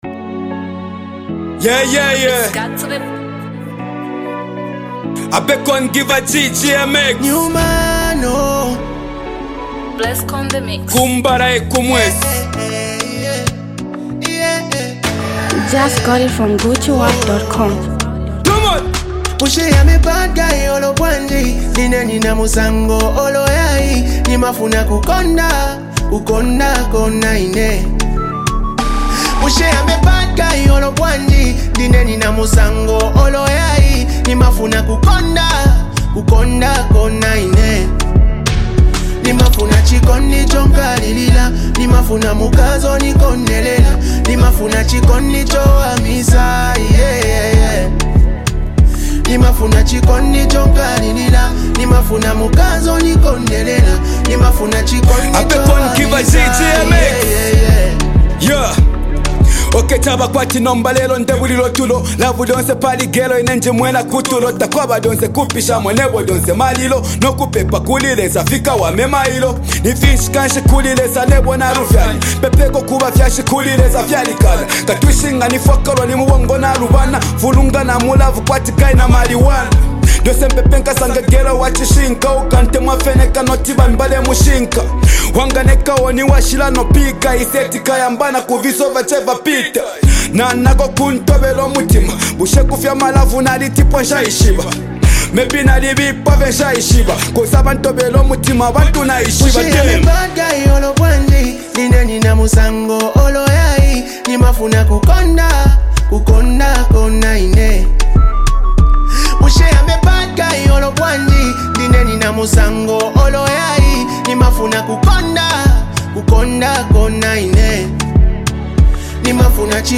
Zambian Mp3 Music
rising talented rap sensation artist.